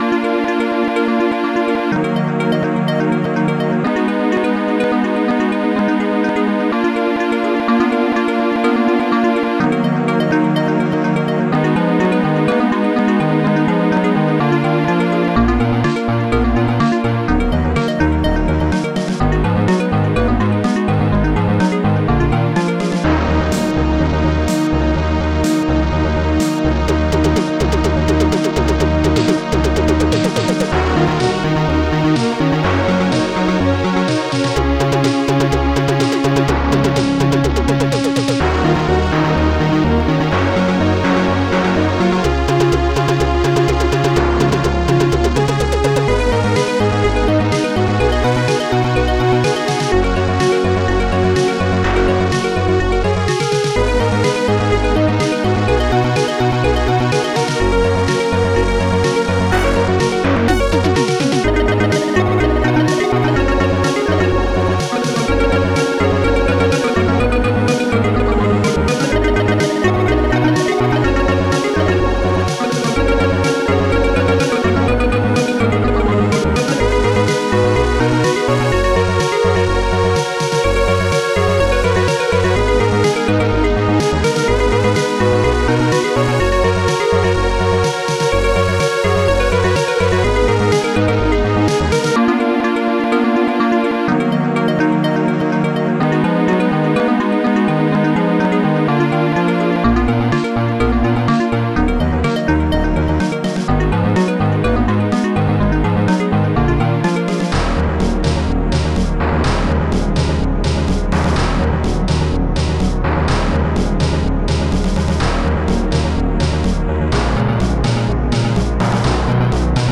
Protracker Module
good song! Type Protracker and family Tracker Noisetracker M.K. Tracks 4 Samples 31 Patterns 18 Instruments st-01:electom esnare st-01: st-01: st-01:popsnare2 monsterbass stringsmaj stringsmin st-01:jans strings0 st-01:heavy